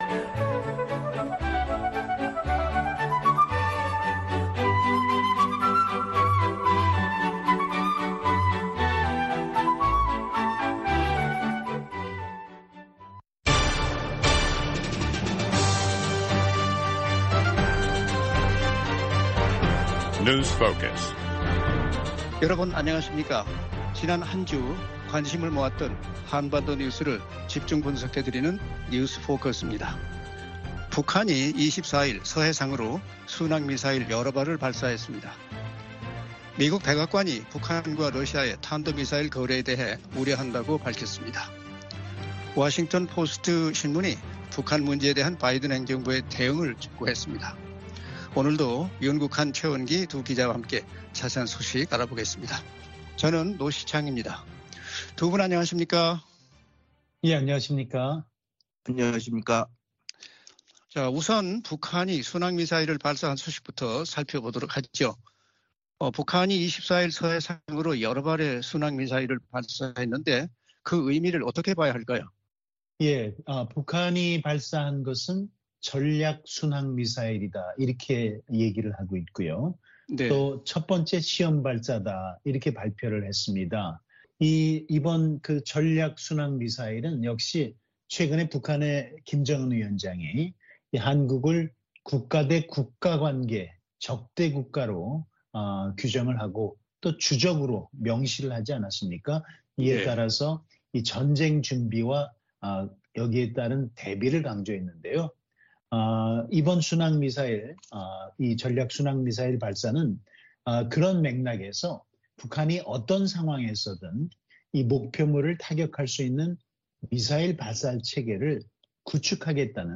VOA 한국어 방송의 월요일 오전 프로그램 2부입니다. 한반도 시간 오전 5:00 부터 6:00 까지 방송됩니다.